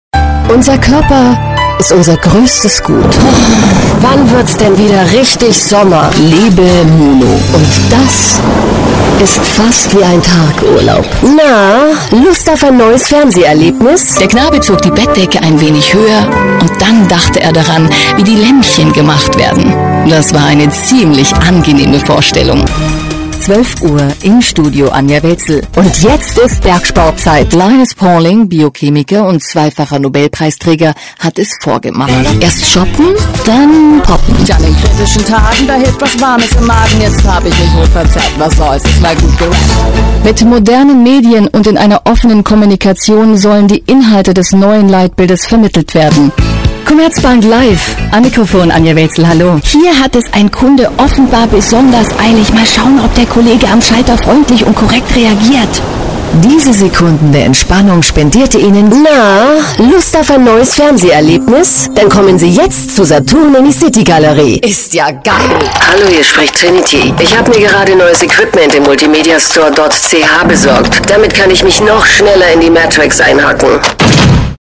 deutsche Werbesprecherin.
Kein Dialekt
Sprechprobe: Sonstiges (Muttersprache):
german female voice over artist.